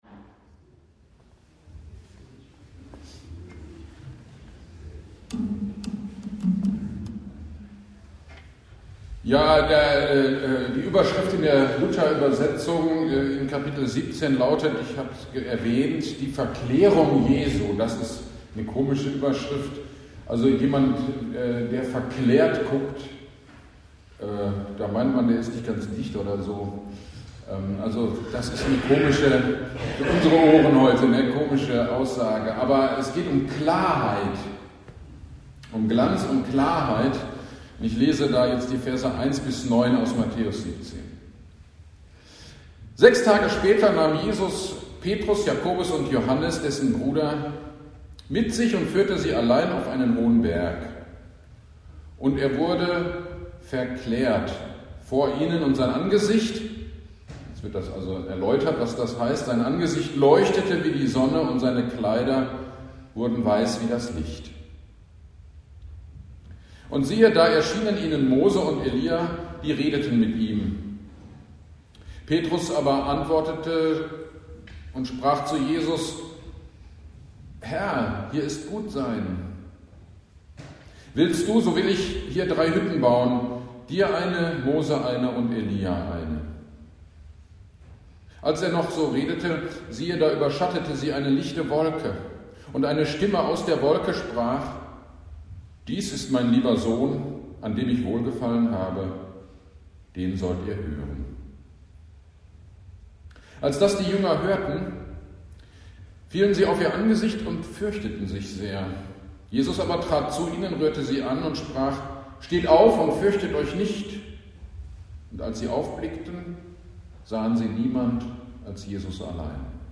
Gottesdienst am 29.01.23 Predigt zu Matthäus 17,1-9 - Kirchgemeinde Pölzig